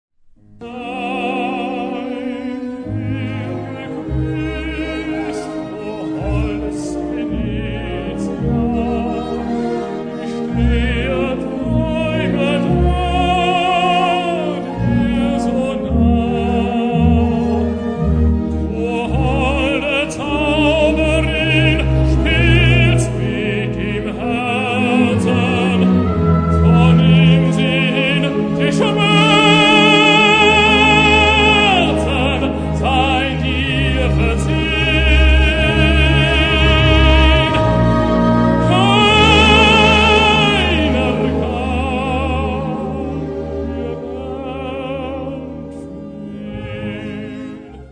Populäre Arien aus Operette – Oper – MusicaL